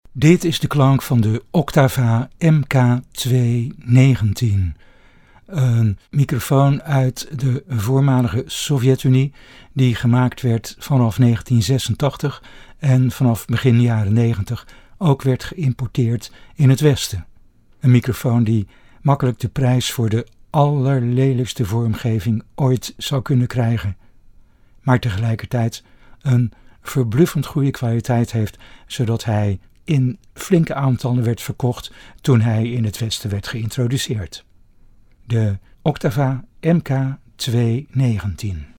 Onder: Klank van de MK 219 en MK 319, MK319 & info
Oktava MK219 sound NL.mp3